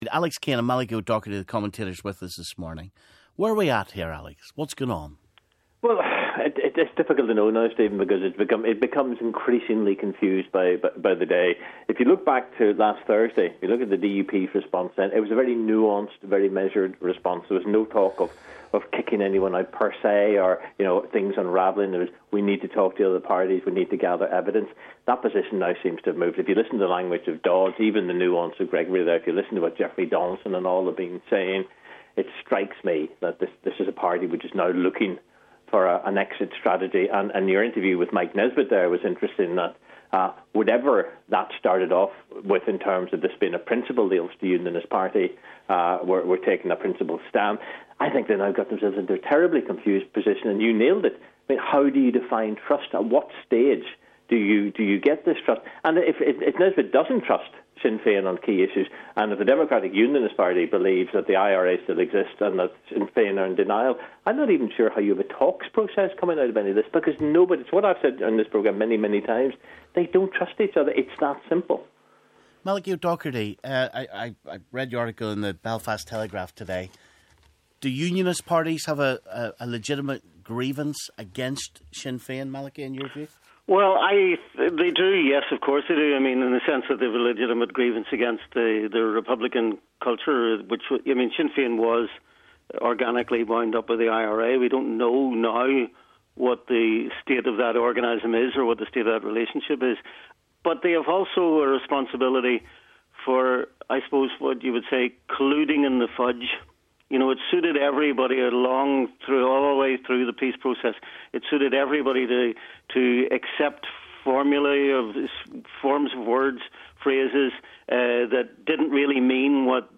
Journalists